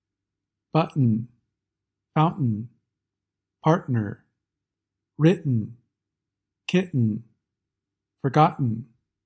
You often get a glottal stop when the letter “t” is after a vowel (or “r”) and before the /n/ sound.